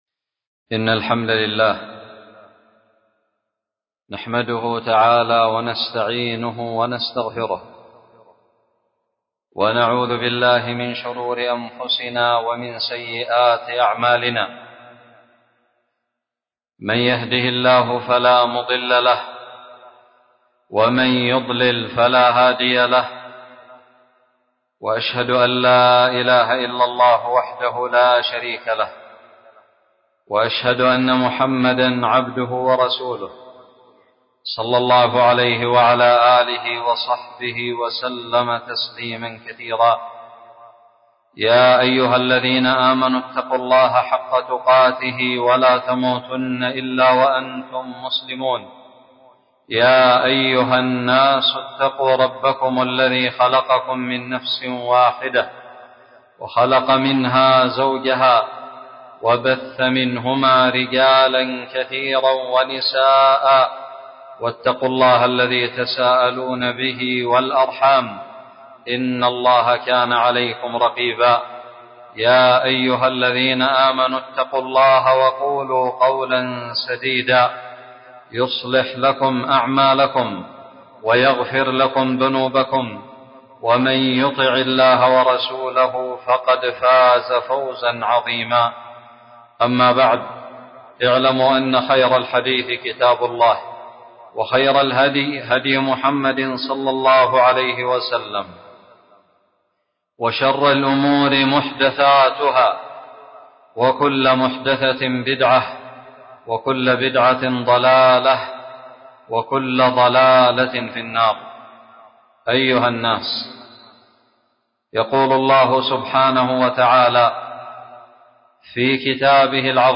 خطب الجمعة
ألقيت في وادي دان يافع